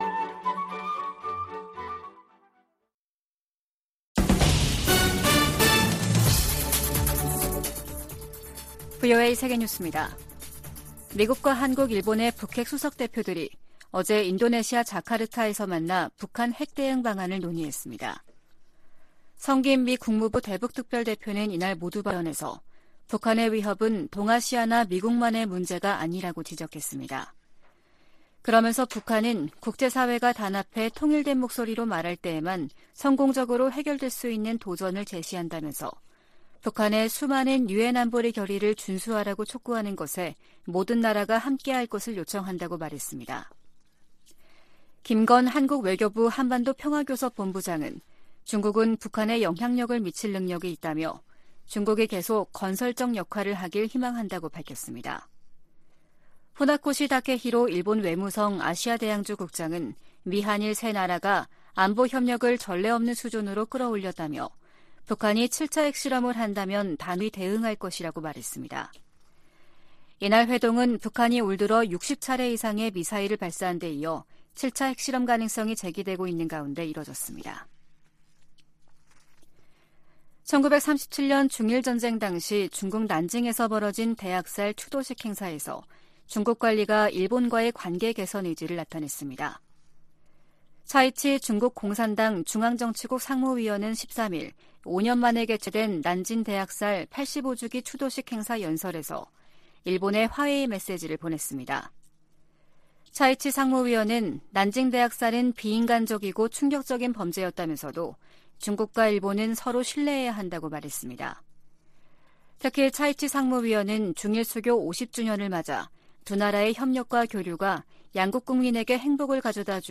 VOA 한국어 아침 뉴스 프로그램 '워싱턴 뉴스 광장' 2022년 12월 14일 방송입니다. 미국과 한국 외교당국 차관보들이 오늘 서울에서 만나 북한 비핵화를 위한 국제사회의 공동 대응을 거듭 강조했습니다. 유럽연합 EU가 북한 김정은 정권의 잇따른 탄도미사일 발사 등에 대응해 북한 국적자 8명과 기관 4곳을 독자 제재 명단에 추가했습니다.